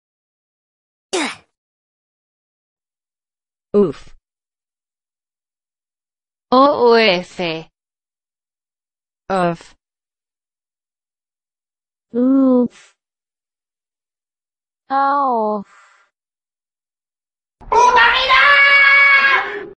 sons de morte do Roblox sound effects free download